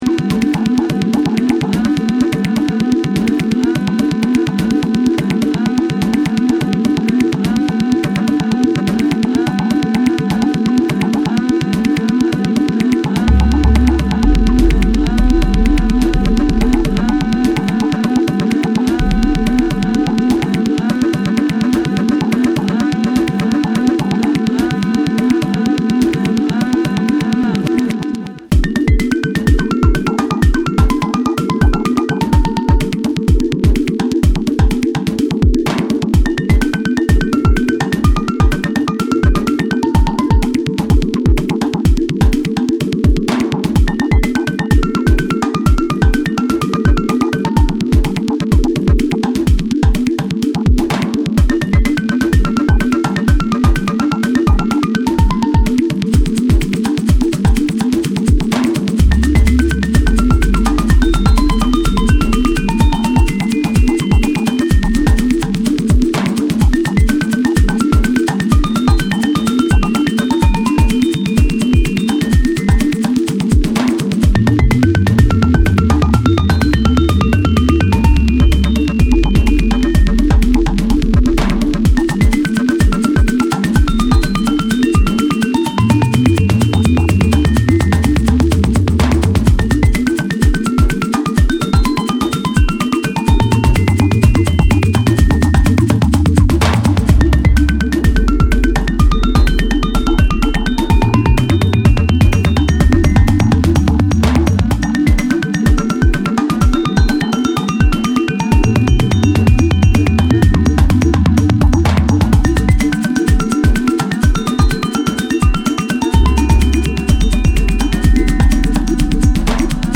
Abstract , Electronic